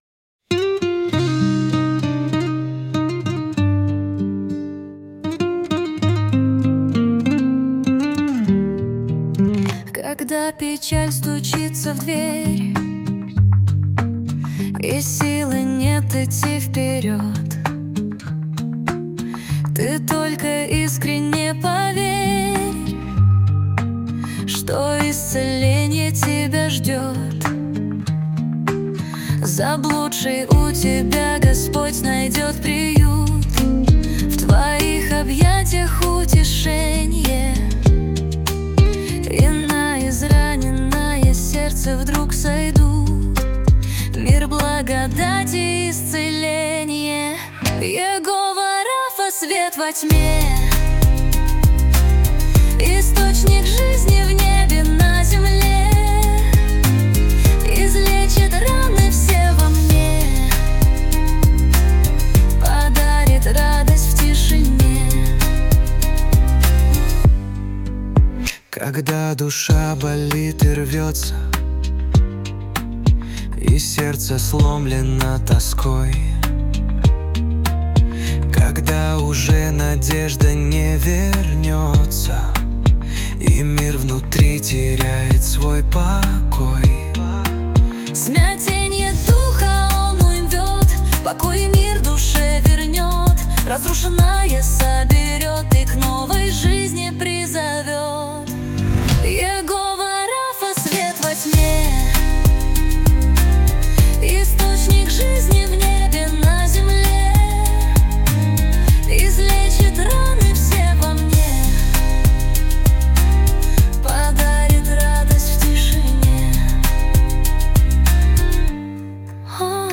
песня ai
232 просмотра 1230 прослушиваний 85 скачиваний BPM: 98